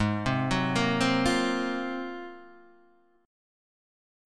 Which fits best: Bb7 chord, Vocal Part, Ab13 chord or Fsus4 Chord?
Ab13 chord